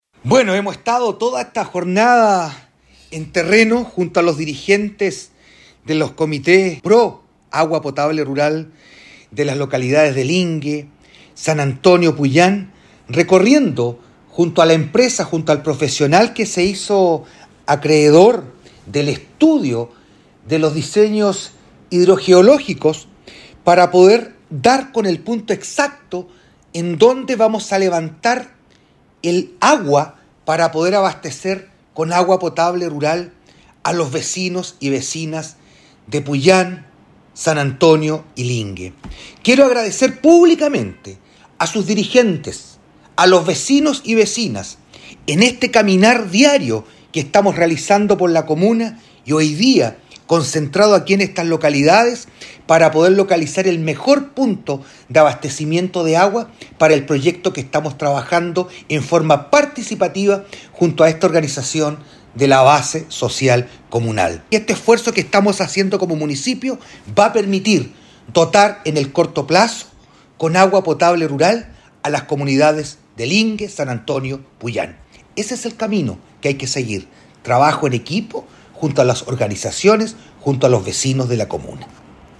ALCALDE-VERA-AUDIO-APR.mp3